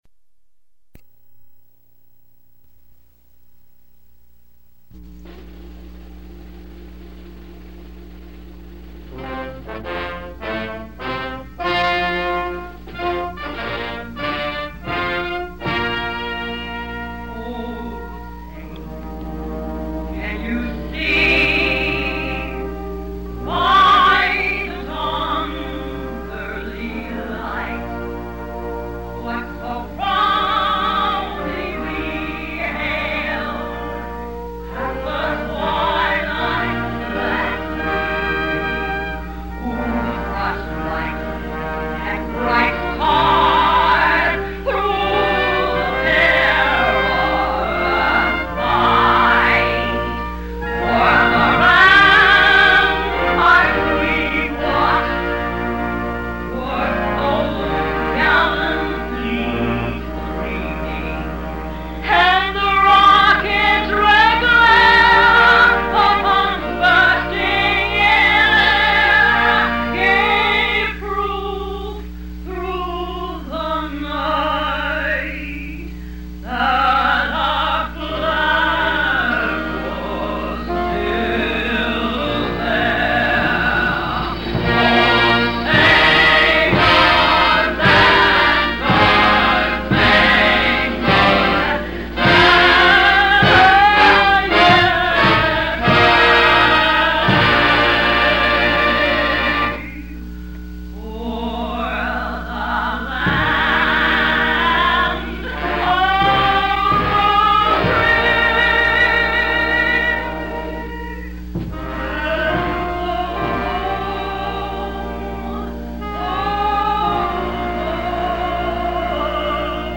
Scope & Content Audio recording containing the dedication ceremony for the Tulsa Port of Catoosa on June 05, 1971.
United States President Richard M. Nixon delivered the principal address. 0:01 national anthem by Anita Bryant
17:45 musical interlude
40:00 speech of President Richard M. Nixon